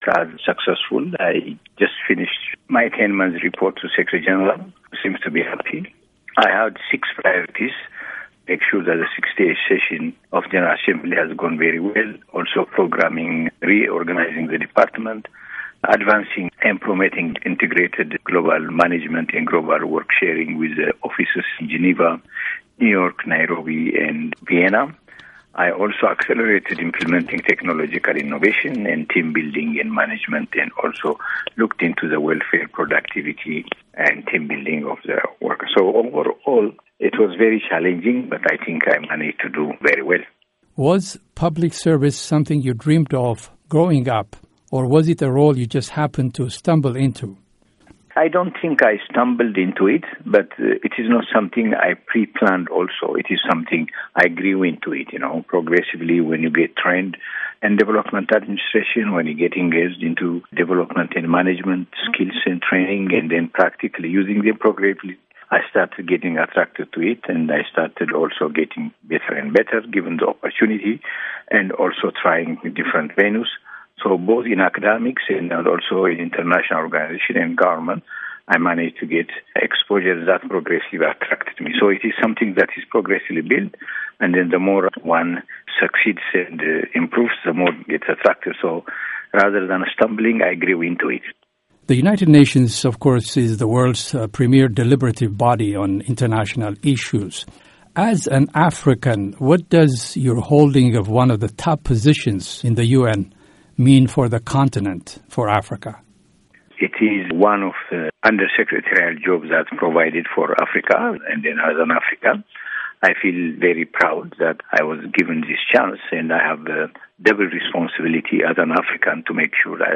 The interview with the UN's Tegegnework Gettu